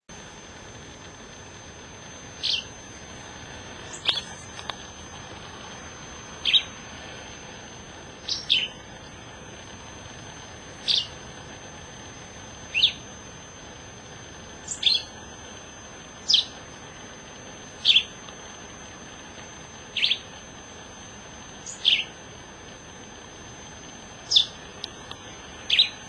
Yellow-green Vireo,  Vireo flavoviridis